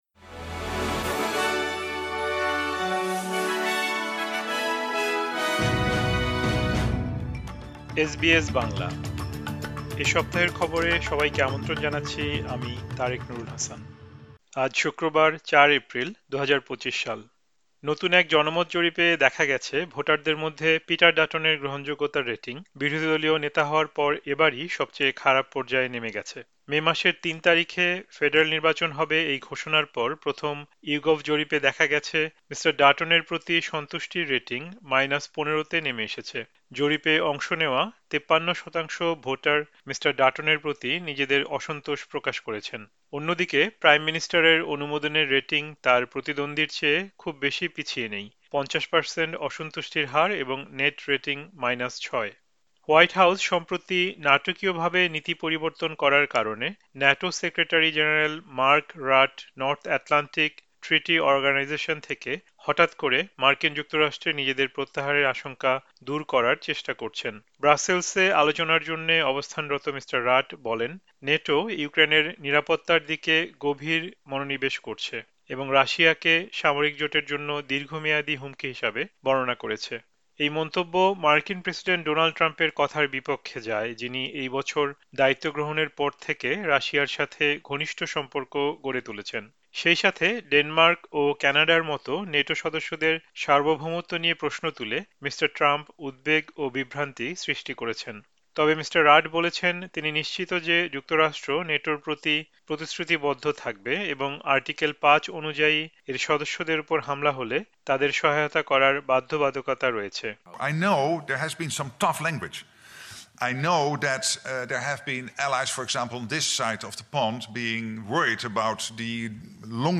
এ সপ্তাহের খবর: ৪ এপ্রিল, ২০২৫